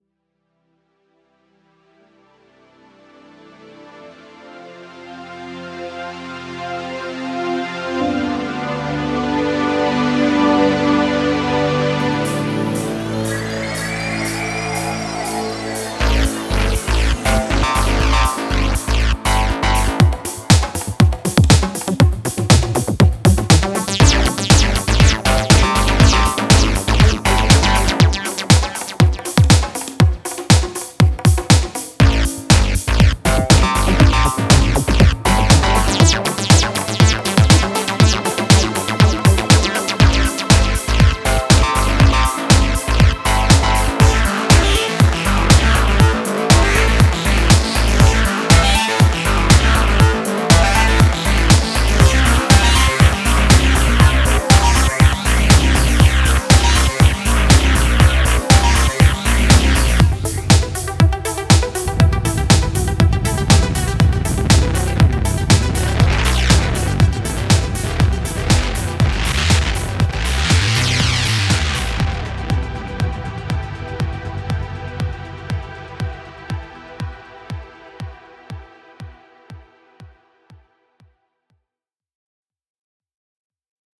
Накидал еще пример, "мульти-пространственный", во всяком случае, так как мне это "видится". Музыкальная составляющая просто материал для эффектов.